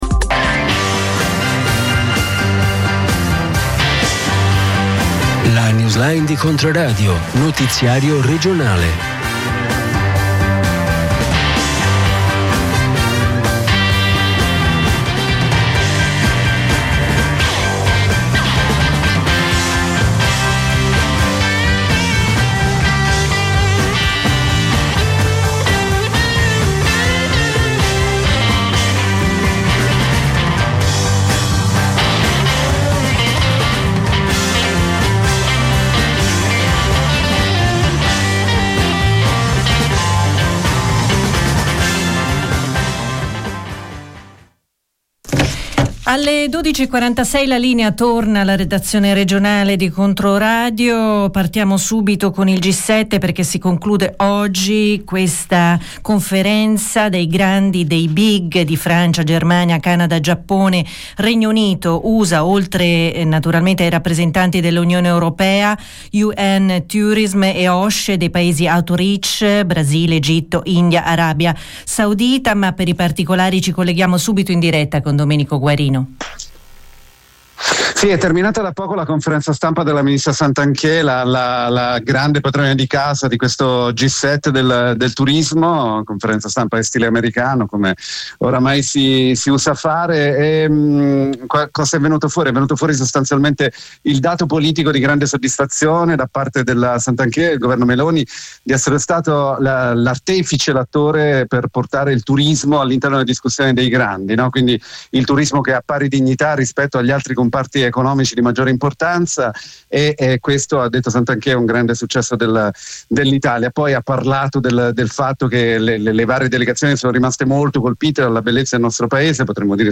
Notiziario regionale